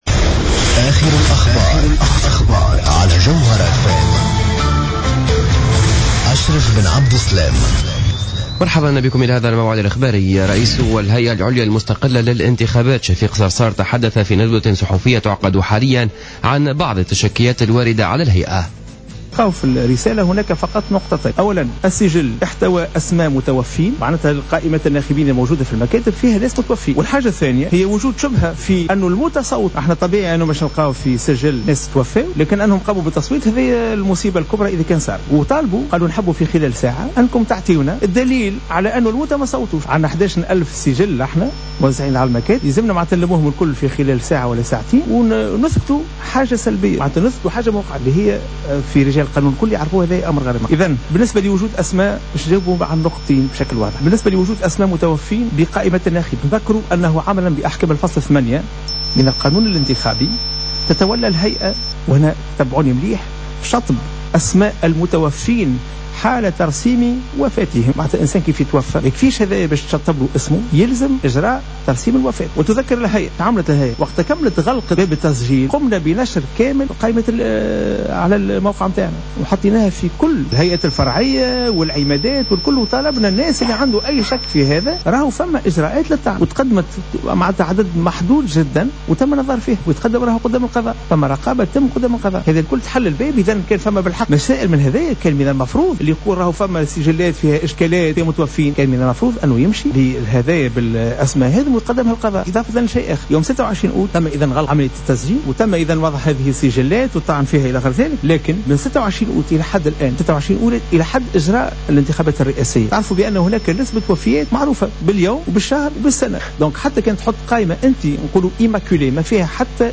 نشرة أخبار منتصف النهار ليوم الجمعة 26-12-14